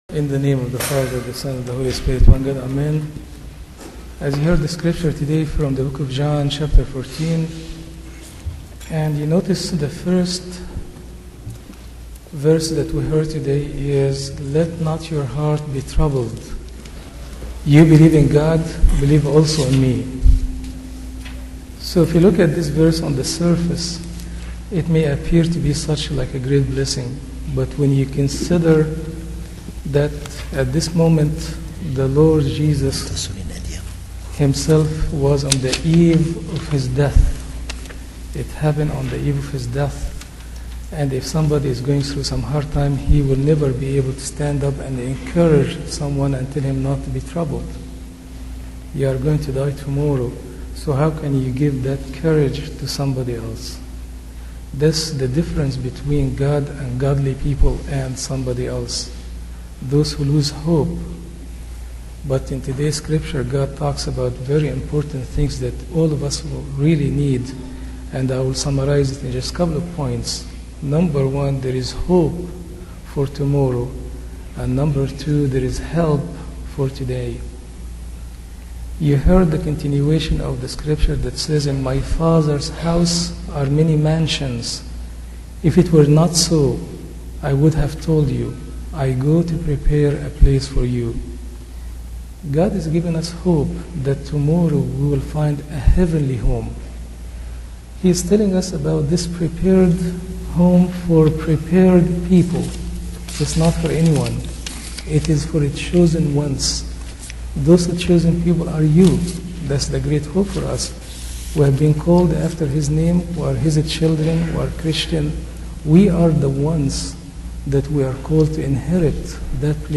A sermon given on May 13, 2007 on John 14:1-11.